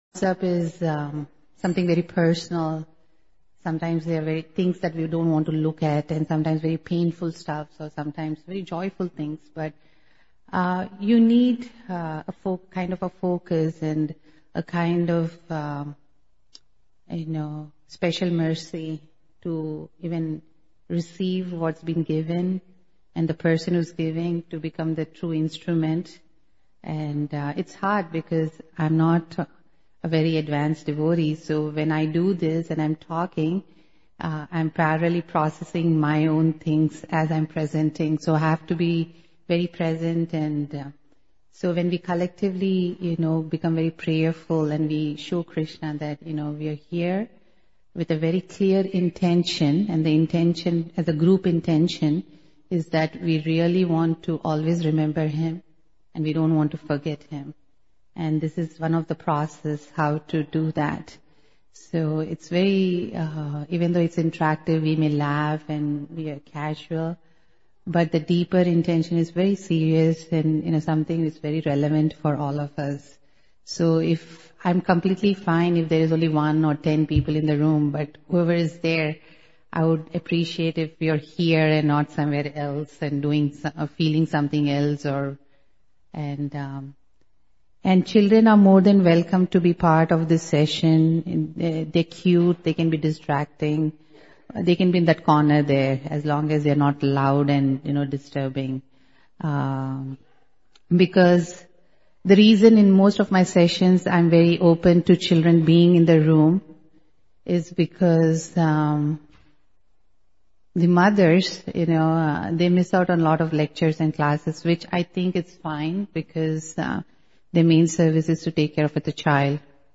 B10_Interactive Session – Self Compassion
New Year Retreat Chicago December 2017